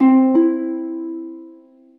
SMS Alert